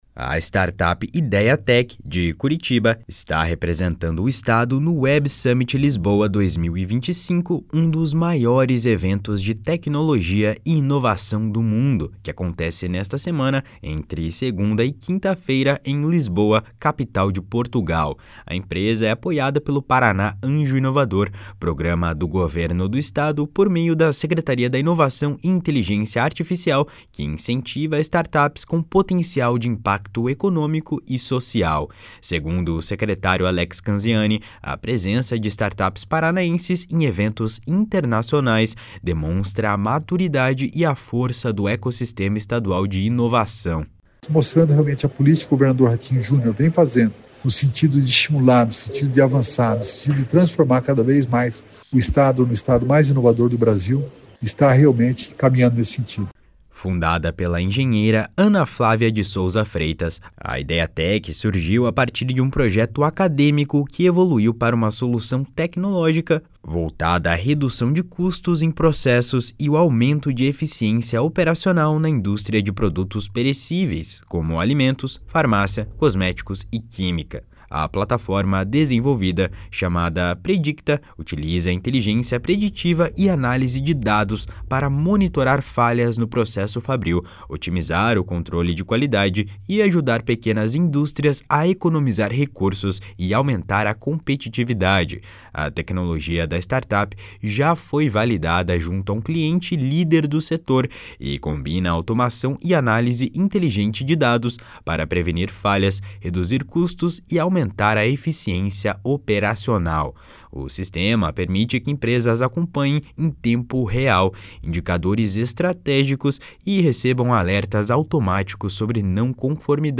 Segundo o secretário Alex Canziani, a presença de startups paranaenses em eventos internacionais demonstra a maturidade e a força do ecossistema estadual de inovação. // SONORA ALEX CANZIANI //